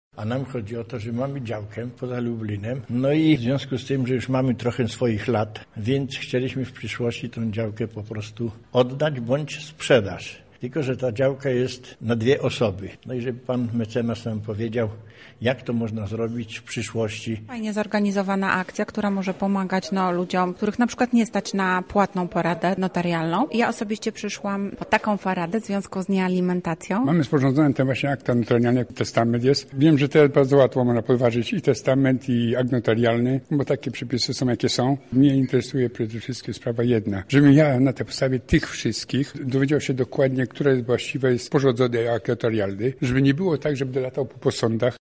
Zapytaliśmy interesantów, o jakie kwestie chcą zapytać specjalistę, podczas spotkania: